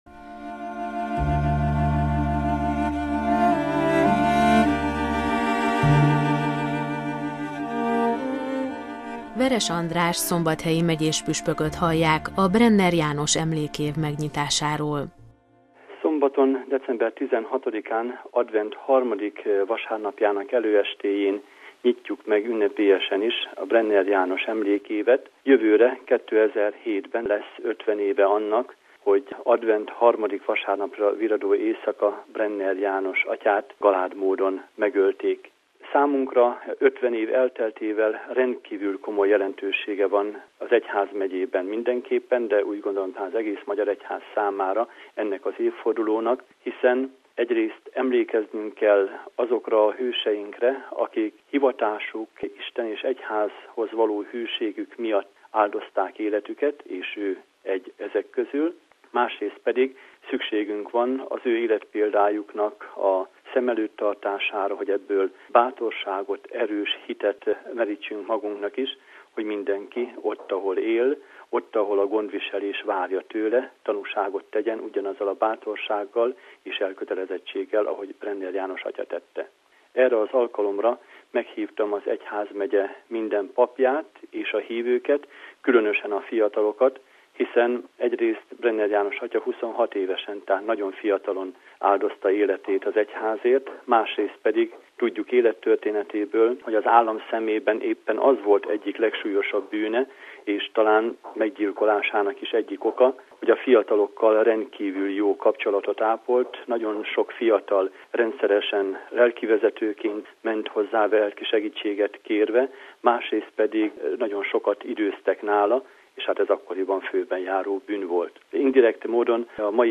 December 16-án, a szombathelyi székesegyházban nyitja meg Veres András szombathelyi megyéspüspök a vértanú pap, Brenner János emlékének szentelt évet. Az évforduló jelentőségéről szól Veres András püspök: RealAudio